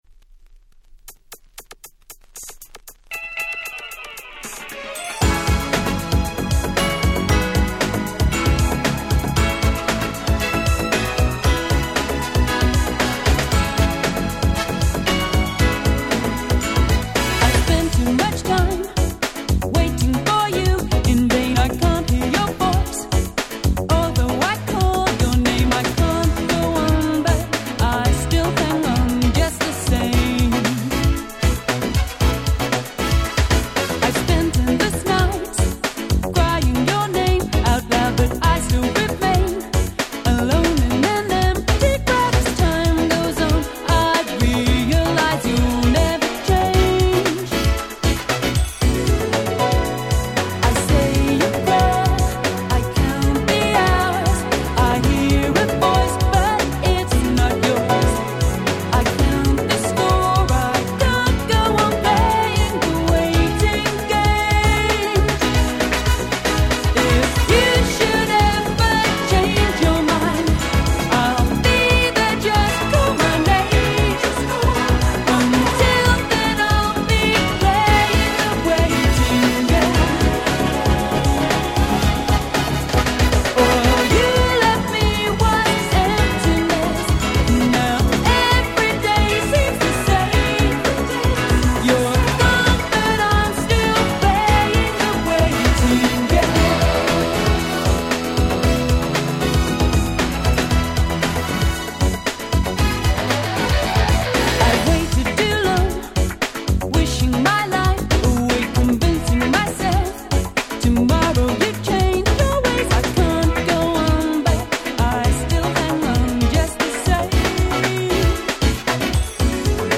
89' Nice UK R&B LP !!